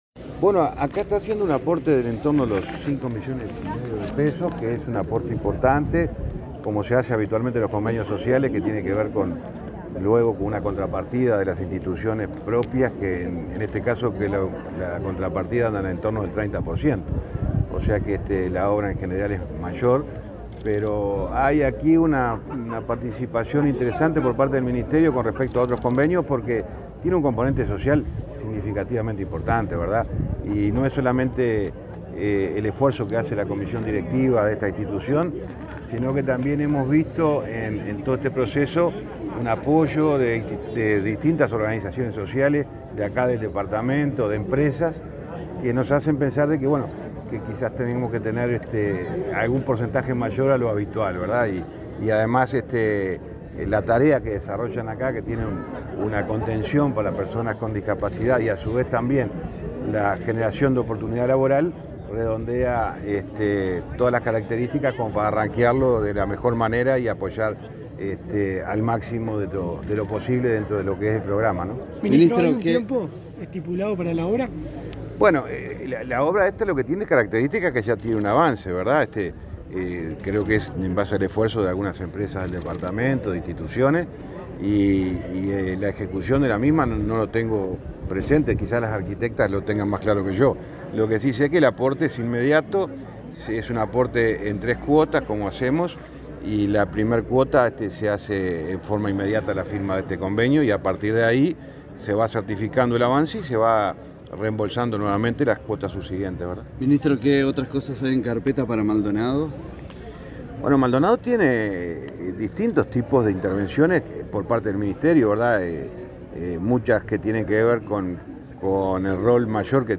Declaraciones a la prensa del ministro de Transporte y Obras Públicas, José Luis Falero
Tras el evento, el ministro efectuó declaraciones a la prensa.